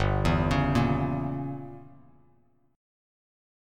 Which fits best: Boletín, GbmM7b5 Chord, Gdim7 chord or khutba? Gdim7 chord